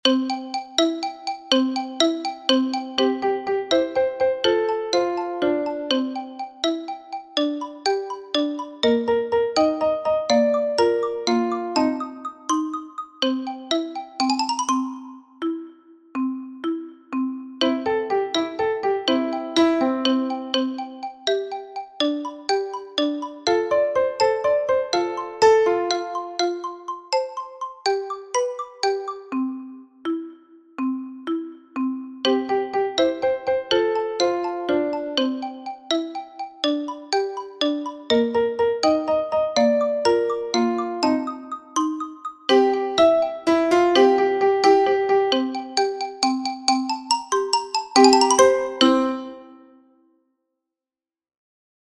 Here you have got the sound file with the silent bars.